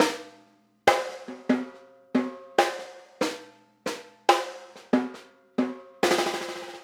Snare Pattern 27.wav